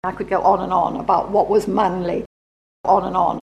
A good way to illustrate this distinction is with native speaker recordings of the common phrase on and on, /ɒn ən ɒn/: